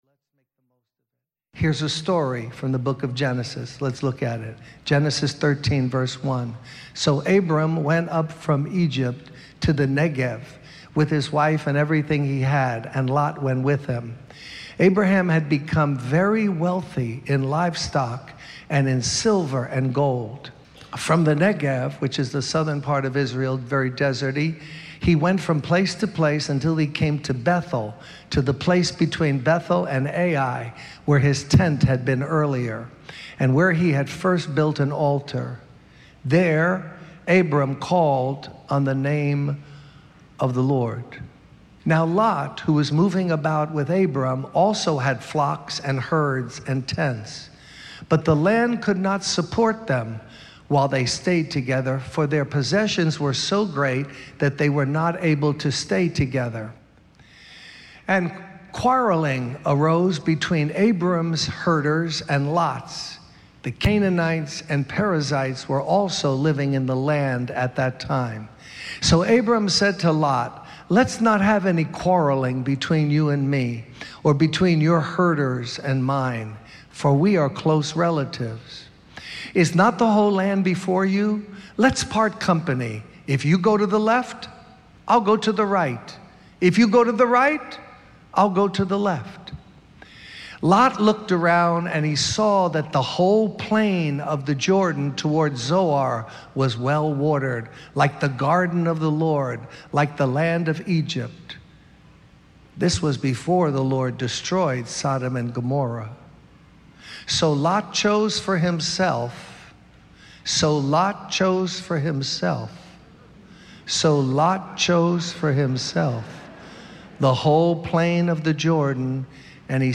In this sermon, the preacher emphasizes the importance of trusting in God and being true to His word. He highlights the negative consequences of people fussing and fighting with each other instead of relying on God. The preacher encourages the congregation to have faith like Abraham and to trust that God will take care of them.